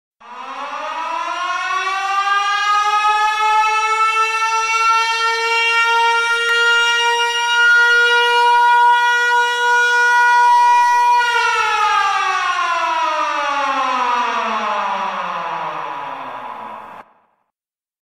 bruitage sirene alarme.mp3
bruitage-sirene-alarme.mp3